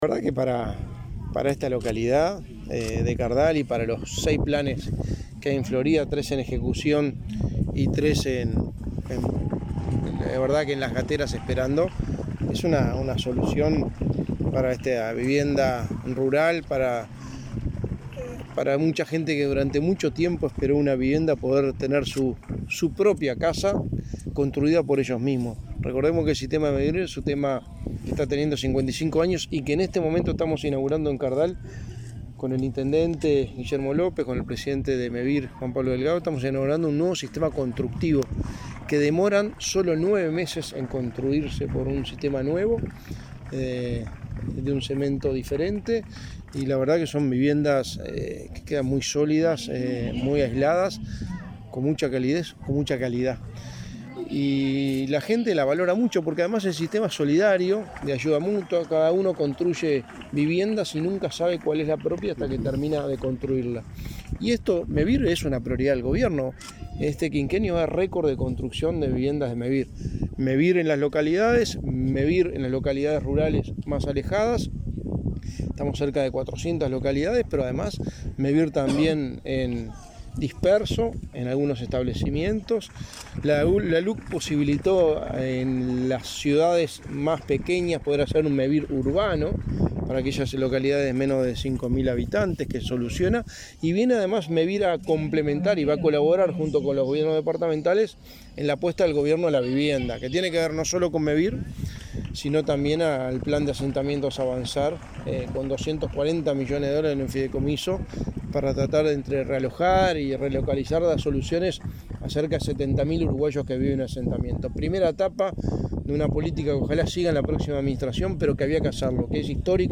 Declaraciones a la prensa del secretario de Presidencia, Álvaro Delgado
Luego, dialogó con la prensa.